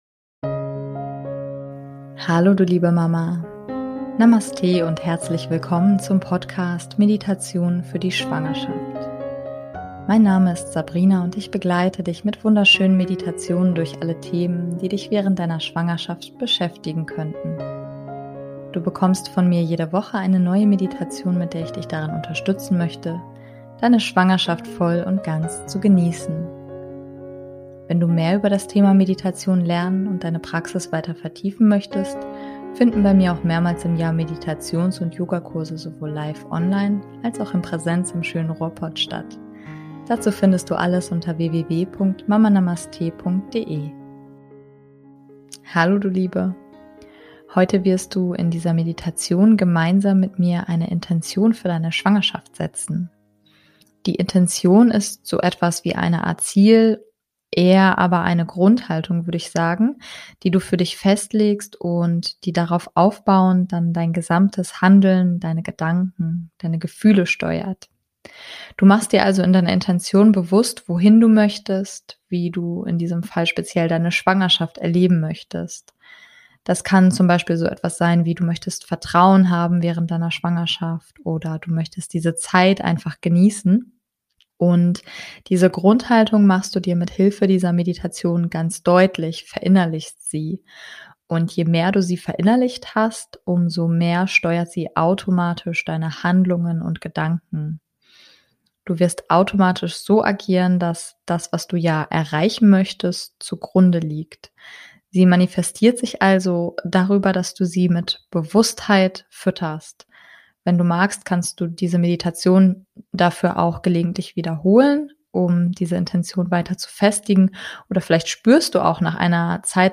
Heute wirst du in dieser Meditation gemeinsam mit mir eine Intention für deine Schwangerschaft setzen.